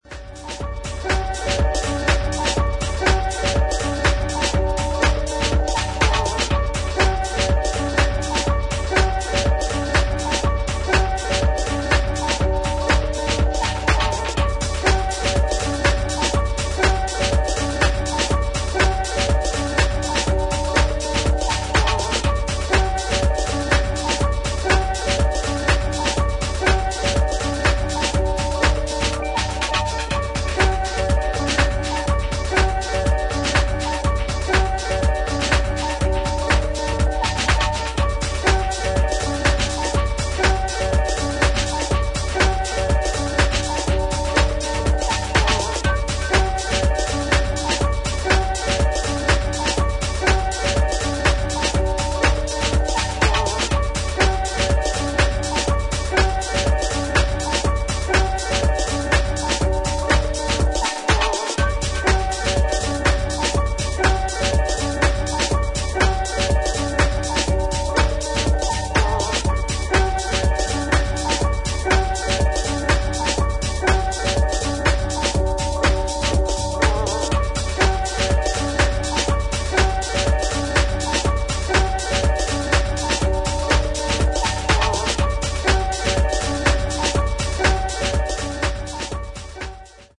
ジャジーな要素をアーティストそれぞれが異なる解釈でフィットさせたディープハウス四曲を収録。